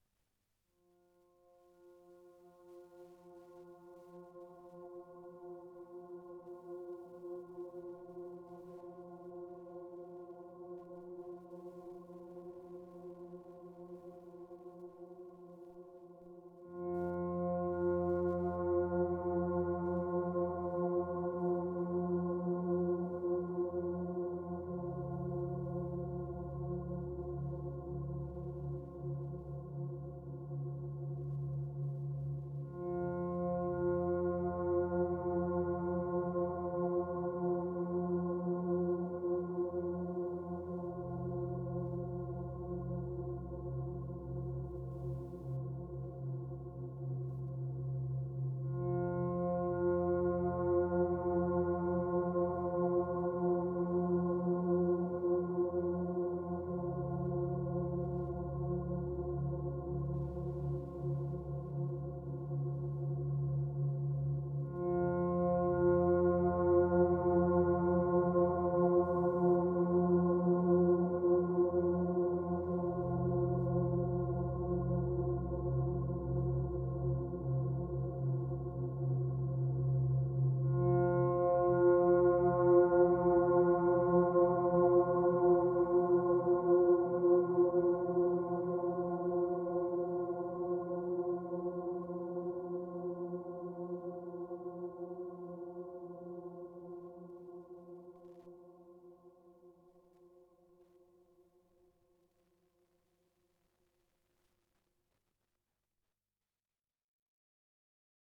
Wailing waves of hazy pads form a thick fog of mystery.